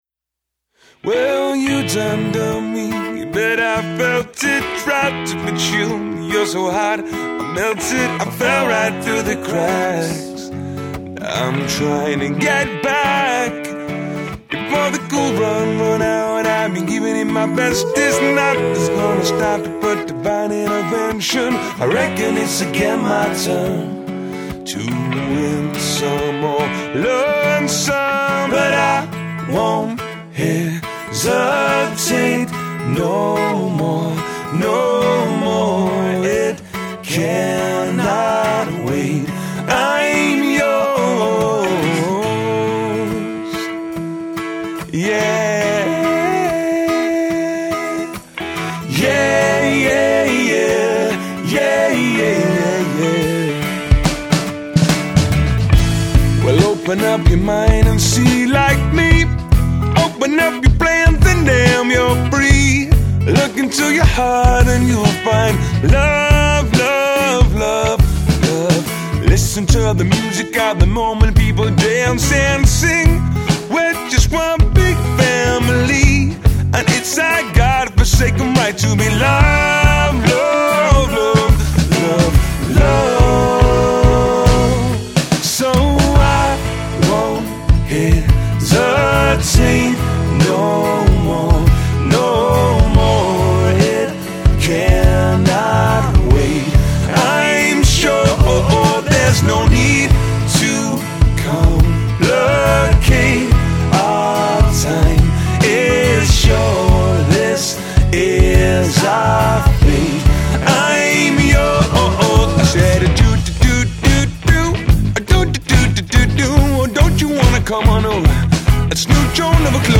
• Three-part harmonies & unique arrangements